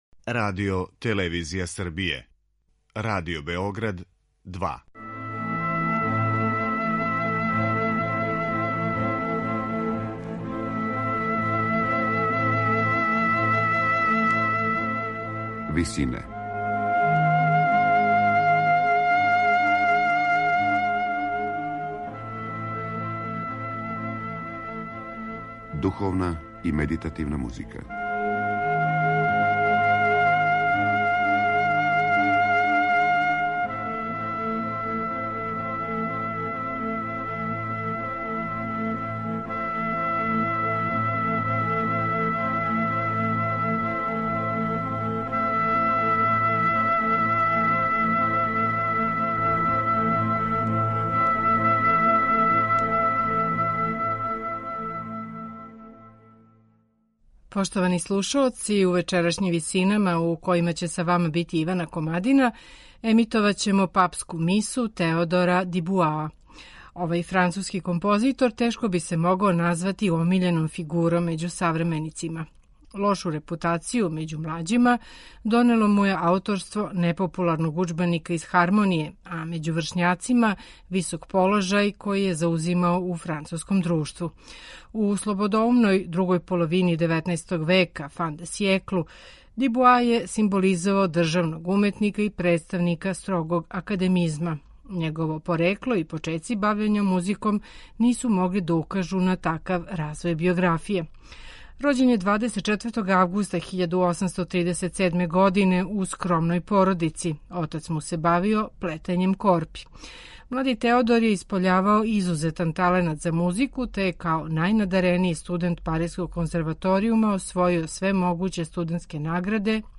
На крају програма, у ВИСИНАМА представљамо медитативне и духовне композиције аутора свих конфесија и епоха.
мецосопран
тенор
баритон
оргуље, хор Фламанског радија и оркестар Бриселске филхармоније